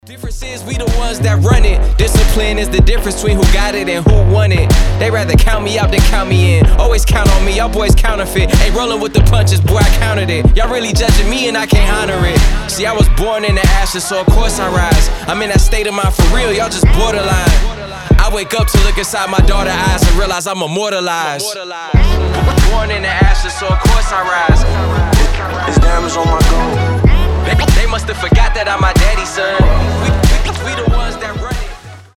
• Качество: 320, Stereo
Хип-хоп